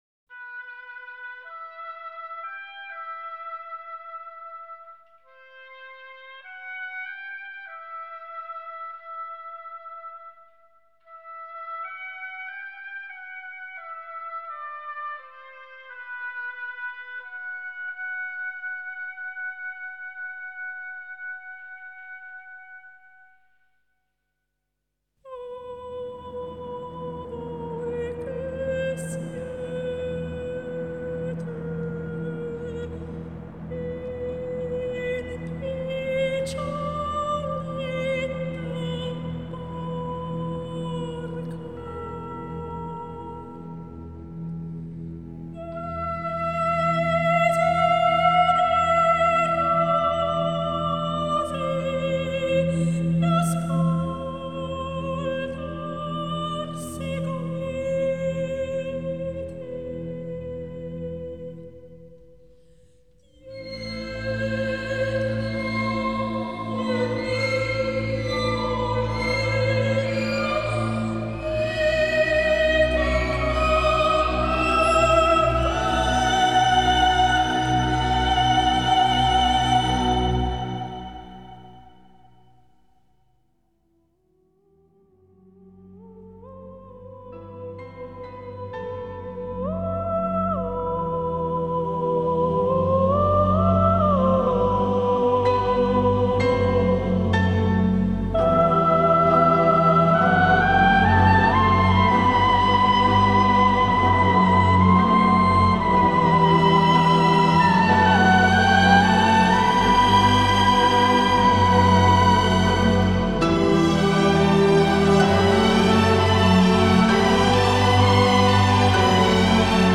纯净的圣洁的女声，与但丁《神曲》中《迈向天堂之歌》的意大利文词配合，意韵悠远，过耳难忘。清越忧伤的笛音与合奏，贯穿始终。